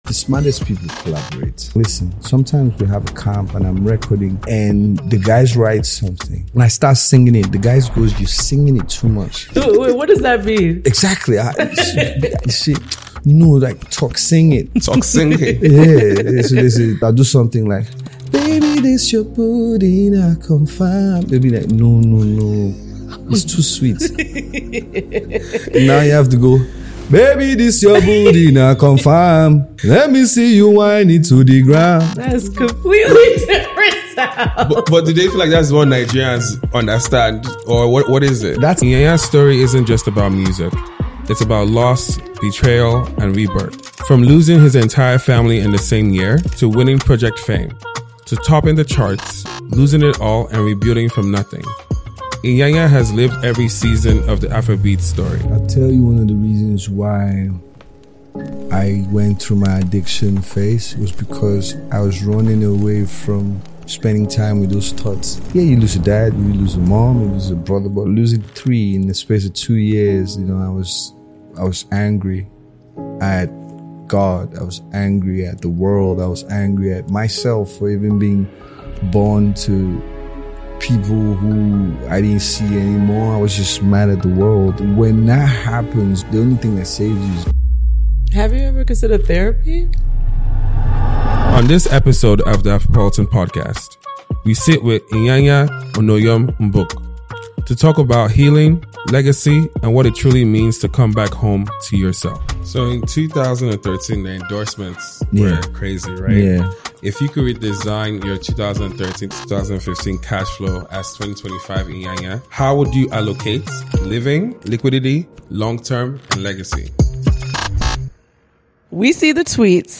Play episode January 28 1h 24m Bookmarks Episode Description Iyanya sat down with The Afropolitan Podcast for one of the most raw, unfiltered conversations we've ever had. 17 years in the music industry.